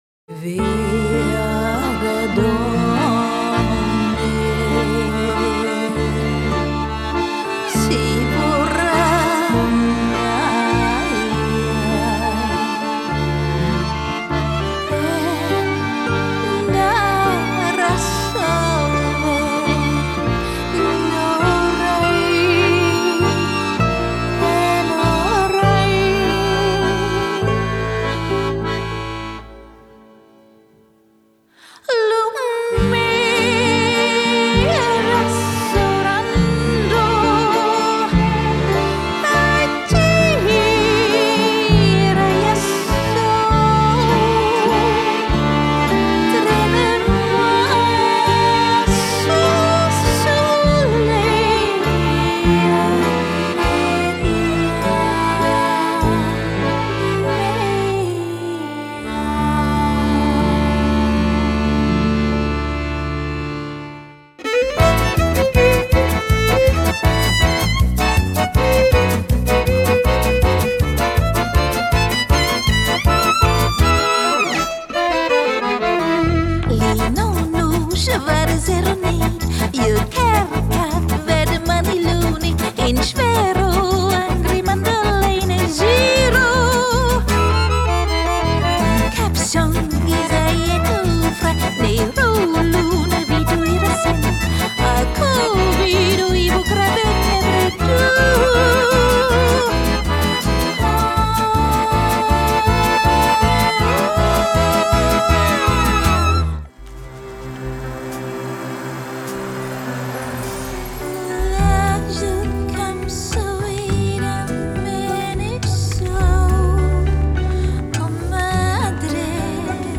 Musik der gleichnamigen Show.
Gesang
Violine
Akkordeon
Percussion
Gitarre
Kontrabass
Audio-Mix